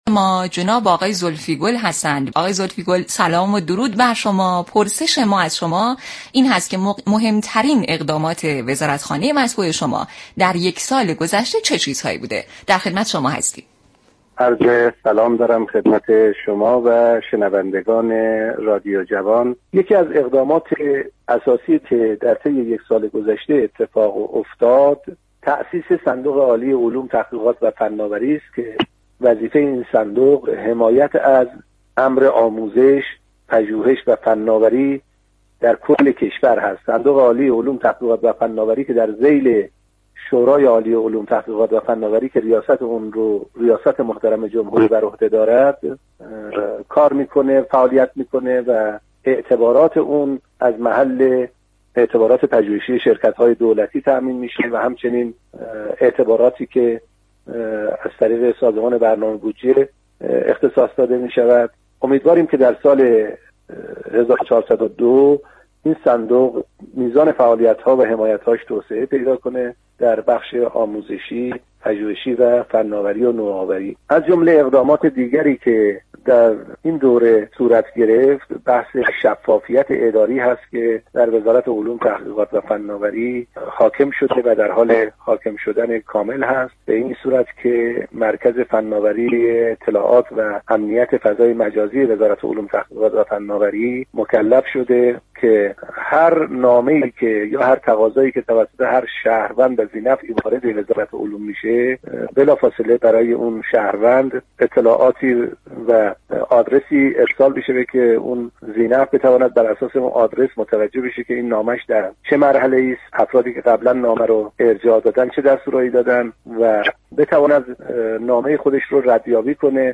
محمد‌علی زلفی گل، وزیر علوم، تحقیقات و فناوری در گفت‌و‌گو با رادیو جوان در برنامه «خط آزاد» درباره مهمترین اقدامات وزارتخانه گفت: یكی از اقدامات اساسی كه در طول یك‌سال گذشته انجام شده، تاسیس صندوق عالی علوم، تحقیقات و فناوری است.